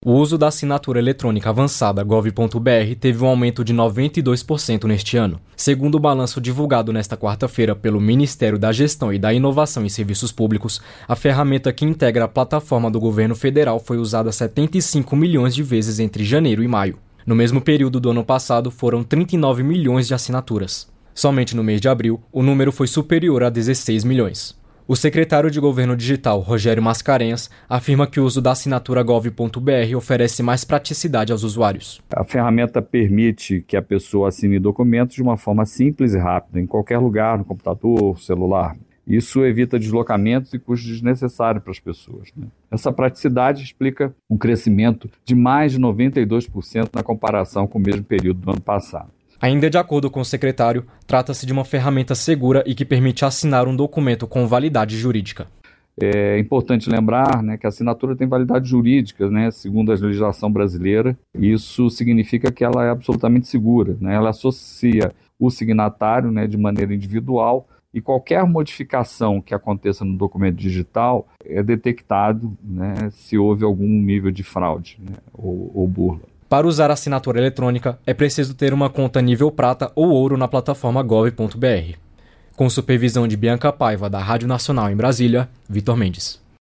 Já o salário mínimo esperado é de R$ 1.630. O secretário de Orçamento Federal, Clayton Montes, explica que esse valor ainda será confirmado: